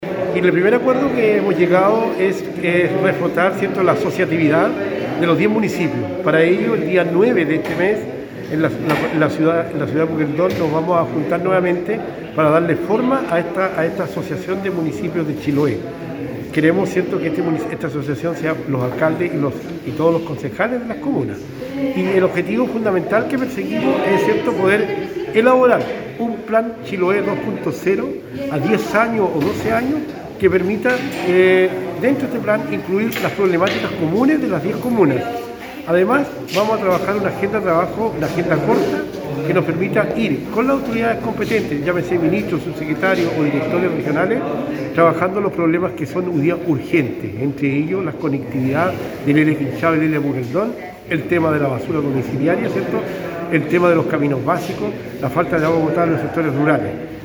Por su parte el alcalde de Dalcahue, Juan Hijerra, expresó que la unidad de las comunas tiene como objetivo principal atender las demandas comunes y solucionarlas de manera conjunta.